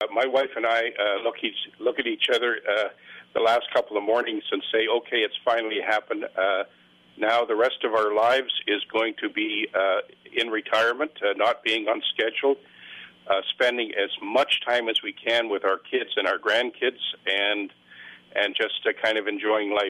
But first, as always, a memorable moment from this morning's show.
Cariboo-Prince George Member of Parliament Dick Harris tells us what its been like to wake up every morning knowing his career in politics is ending after 21 years.